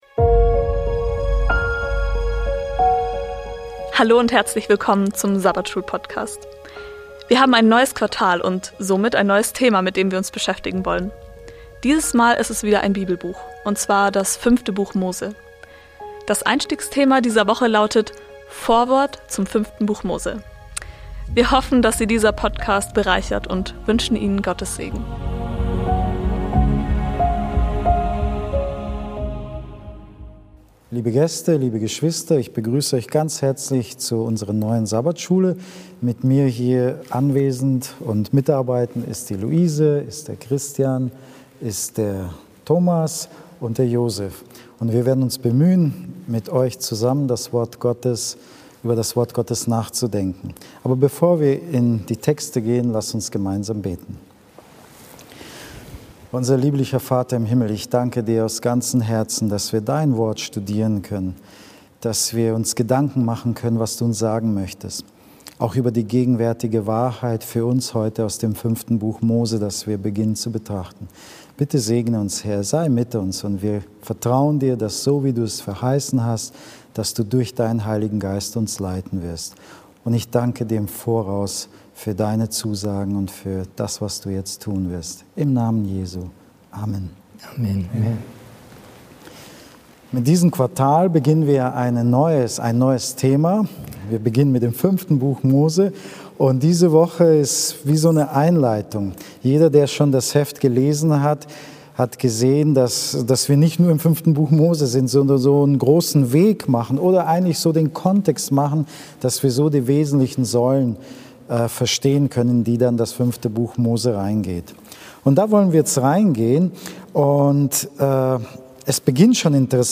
Hier hören sie das Sabbatschulgespräch aus Bogenhofen zur Weltfeldausgabe der Lektion der Generalkonferenz der Siebenten-Tags-Adventisten